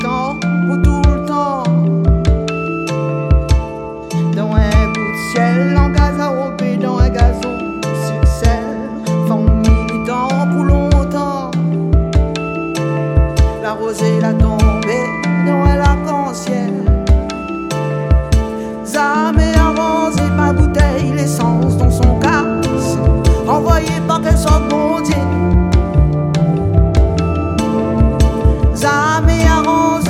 électro maloya